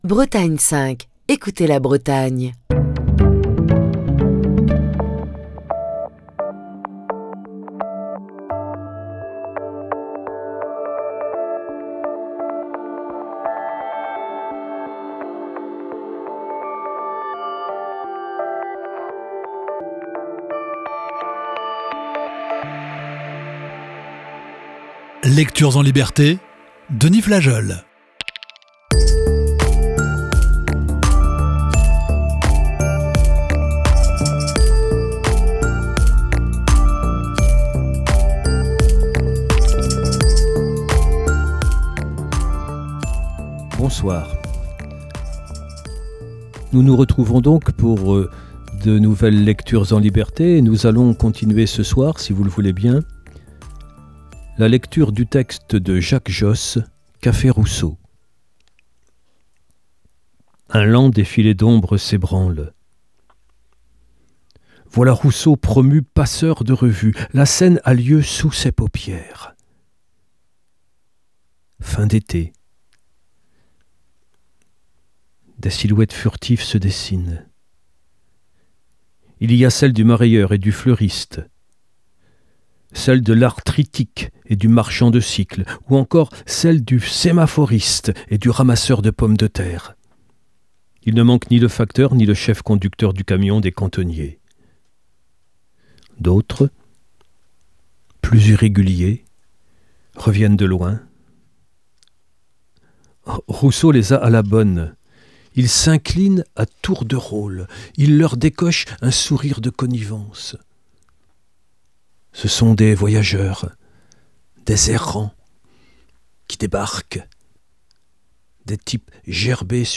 la lecture de la deuxième partie de "Café Rousseau", un récit de Jacques Josse.